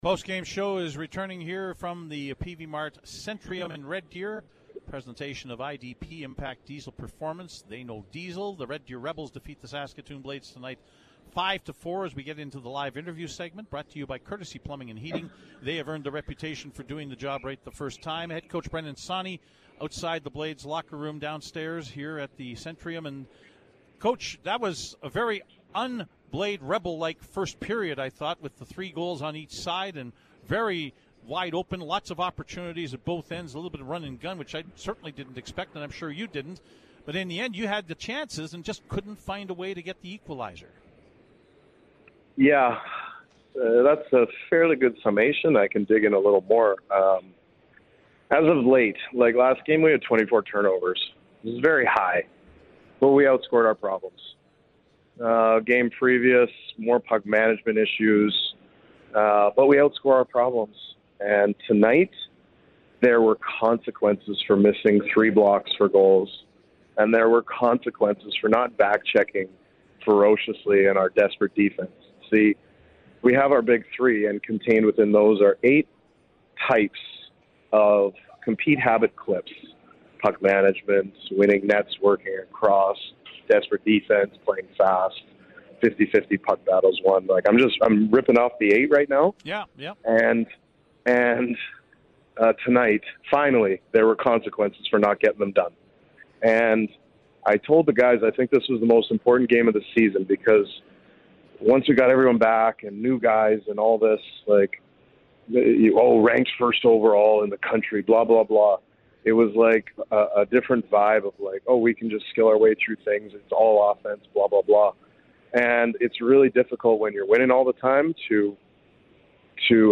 Post-Game Show conversation